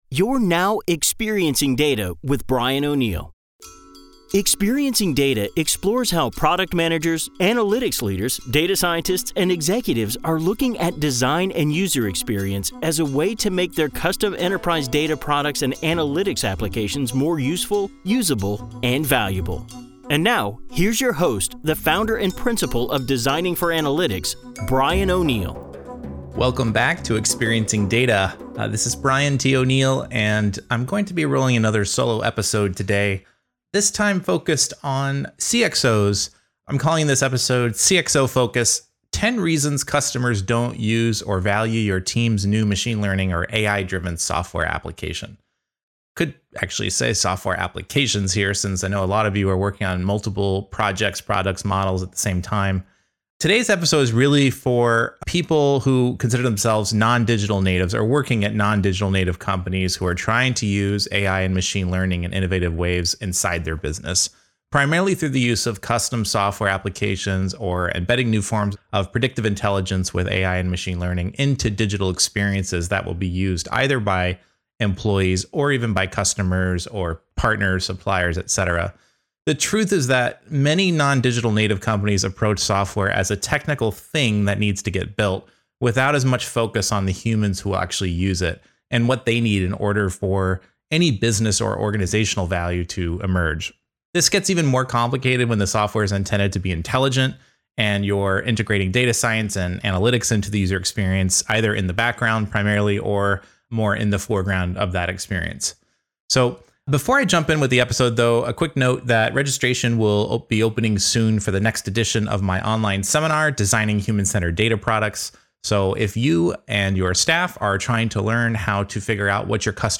Watch Now Welcome back for another solo episode of Experiencing Data.